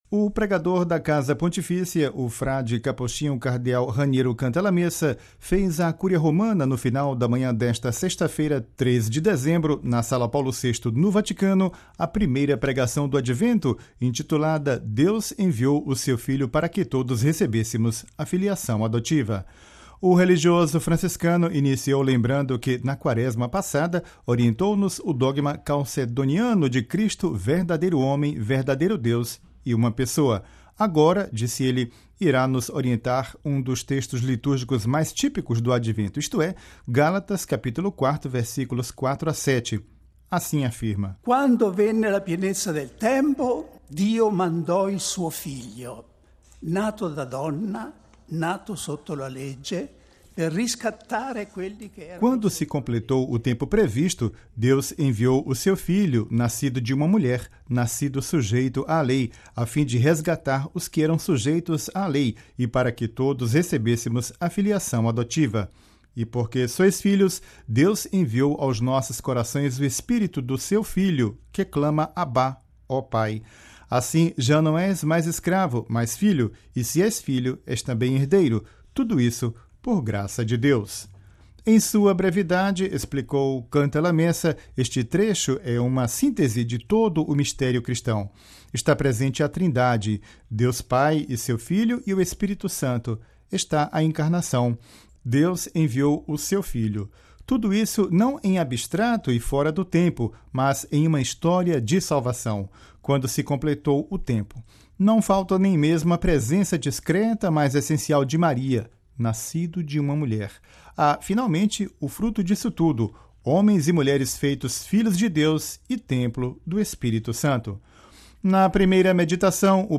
O pregador da Casa Pontifícia, o frade capuchinho cardeal Raniero Cantalamessa, fez à Cúria Romana no final da manhã desta sexta-feira (03/12), na Sala Paulo VI, no Vaticano, a Primeira Pregação do Advento, intitulada “Deus enviou o seu Filho para que todos recebêssemos a filiação adotiva”.